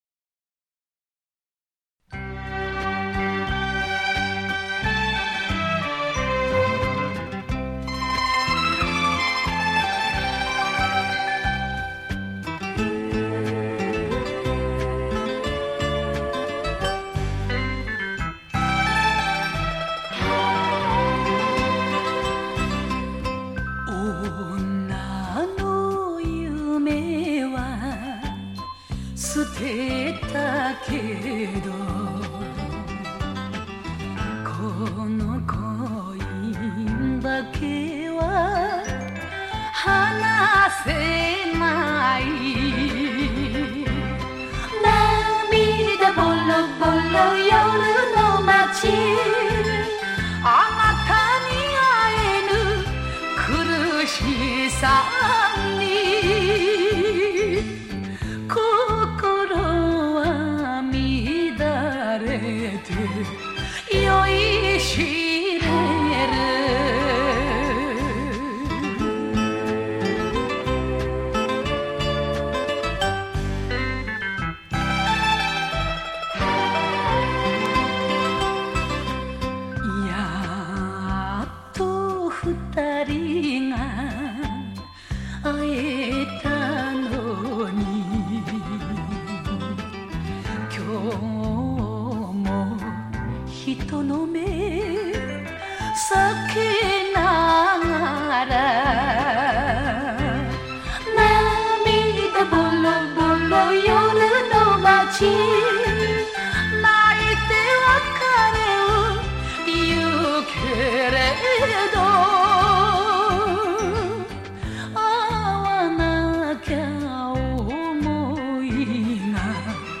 收录日本演歌精选/曲曲动听 朗朗上口